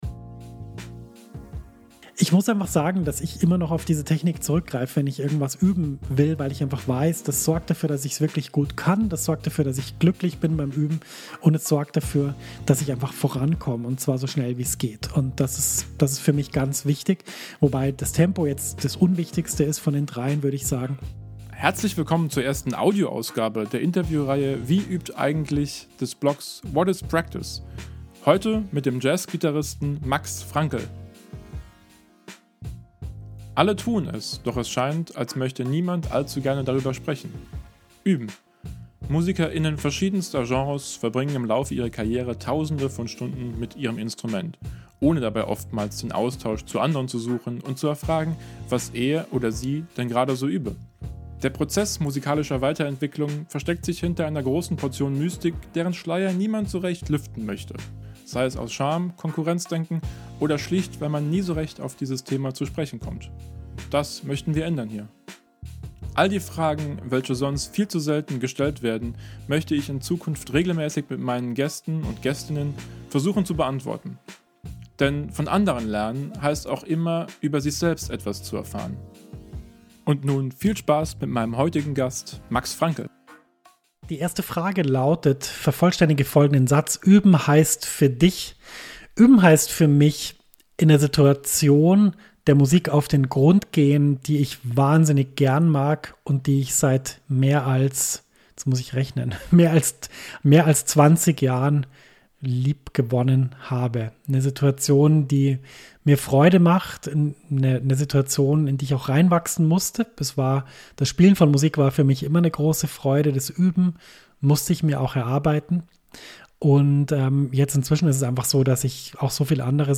(SPEZIAL MONOLOG-FOLGE)